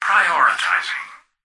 "Prioritizing" excerpt of the reversed speech found in the Halo 3 Terminals.
H3_tvox_hex6_prioritizing_(unreversed).mp3